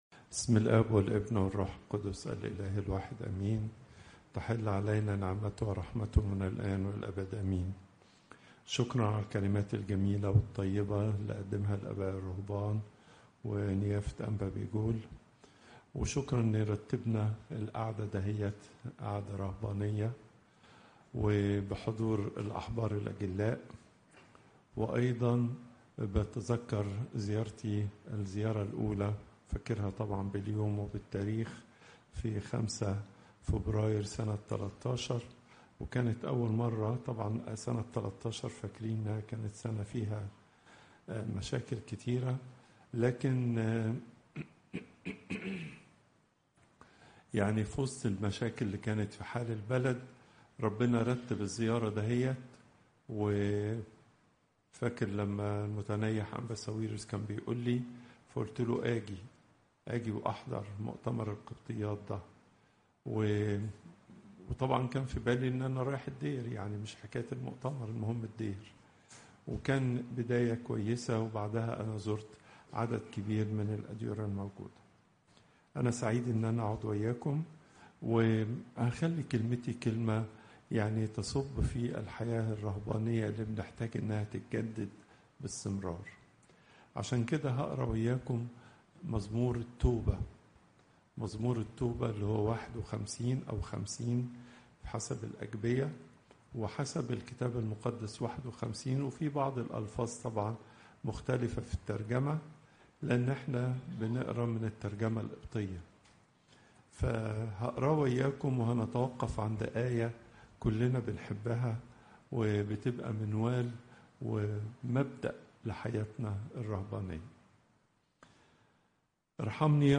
Popup Player تحميل الصوت البابا تواضروس الثانى الأربعاء، 01 أكتوير 2025 39:32 المحاضرة الأسبوعية لقداسة البابا تواضروس الثاني الزيارات: 162